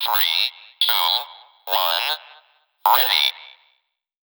"Резиновая" обработка голоса
Здесь тоже Битспик, обработанный эквалайзером, или что другое, как думаете?